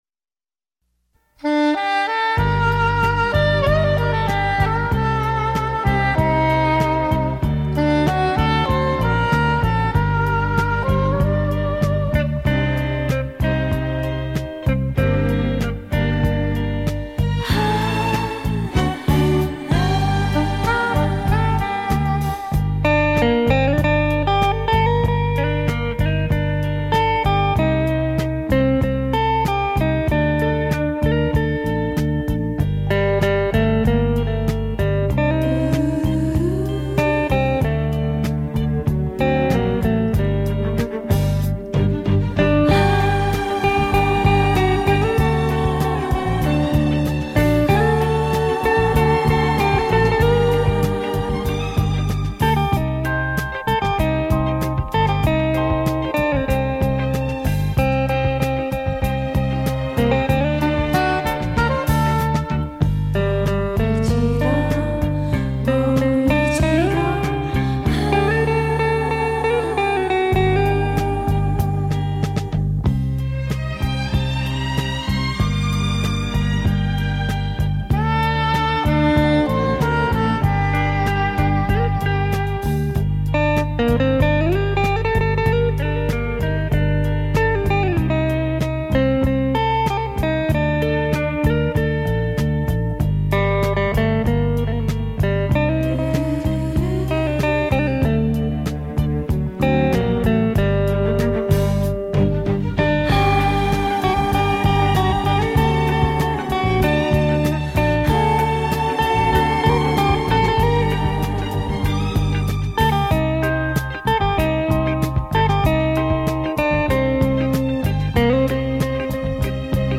畅销东洋演歌名曲演奏